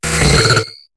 Cri de Loupio dans Pokémon HOME.